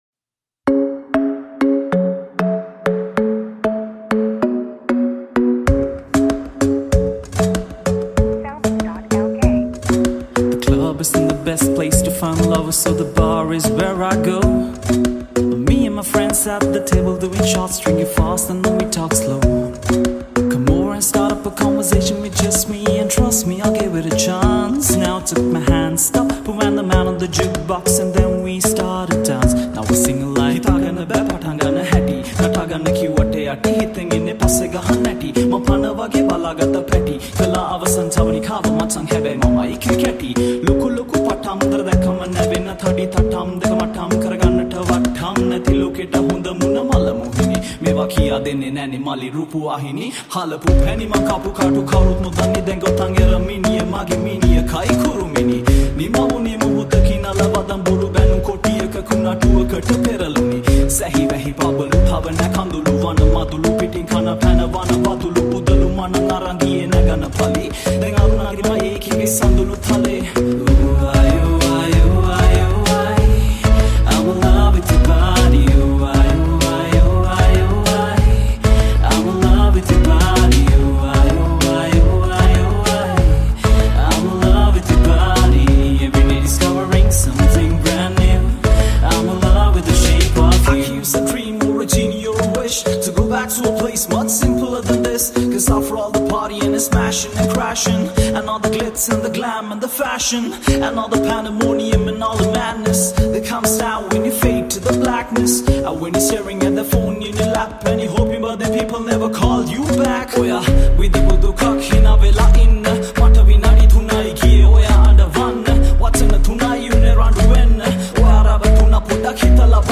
High quality Sri Lankan remix MP3 (4).